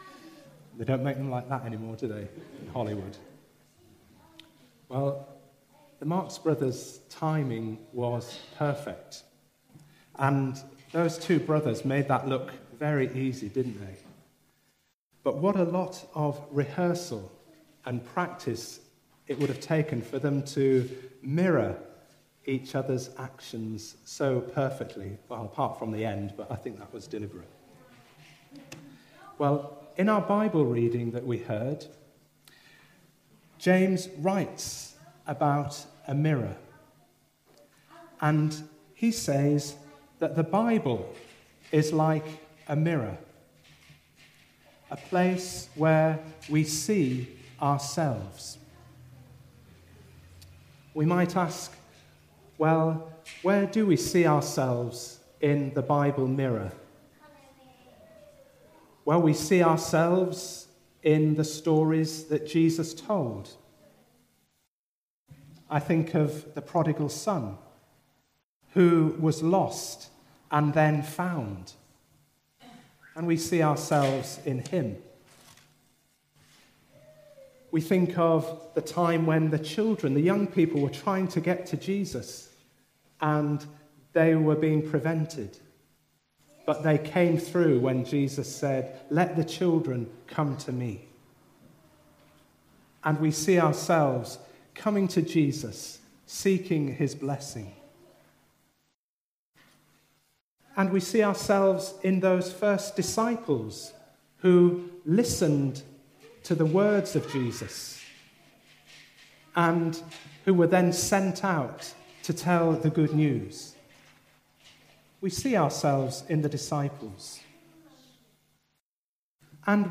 James 1:19-22,23-26,27 Service Type: All Age Worship The first of four Sundays studying the book of James.